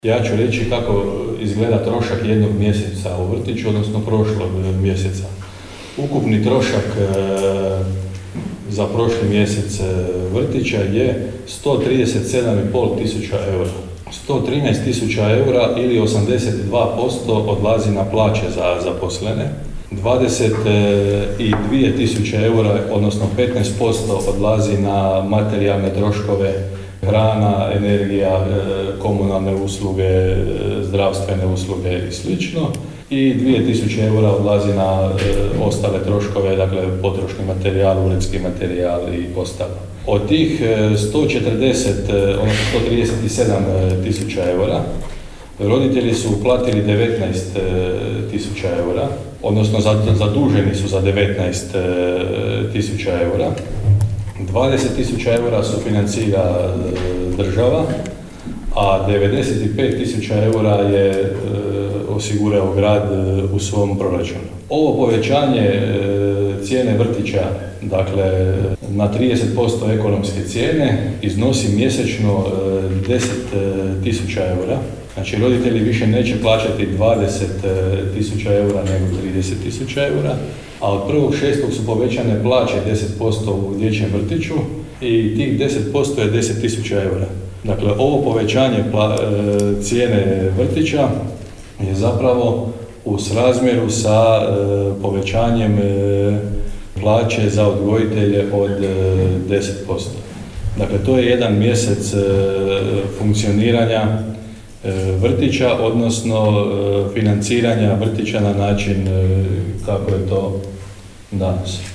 rekao je gradonačelnik Hrvoje Janči